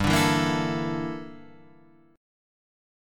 G Minor 6th Add 9th
Gm6add9 chord {3 5 2 3 5 5} chord